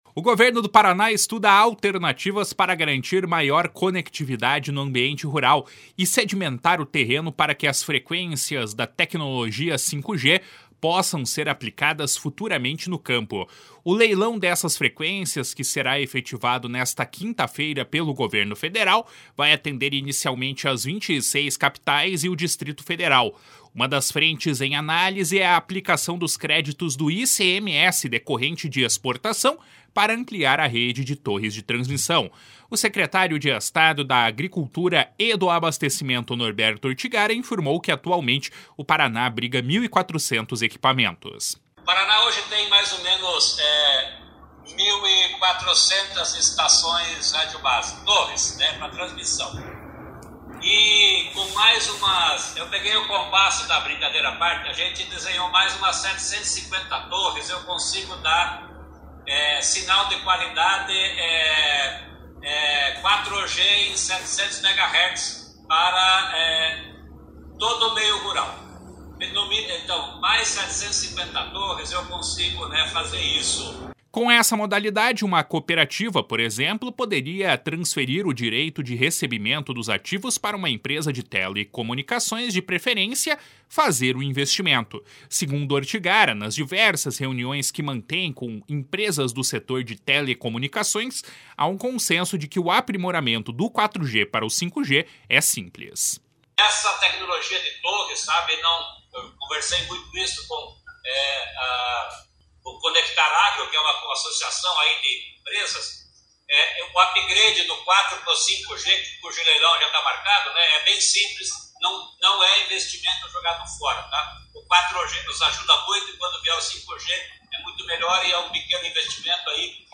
// SONORA NORBERTO ORTIGARA //